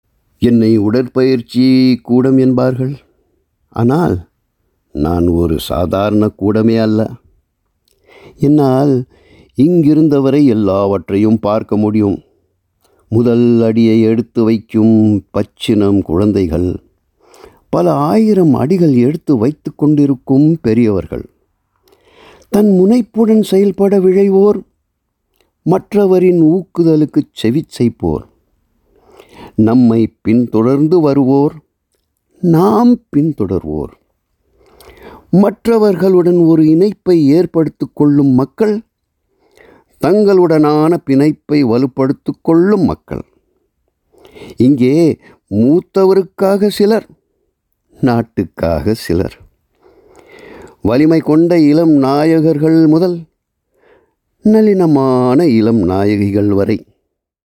Voice Samples: Voice Sample 01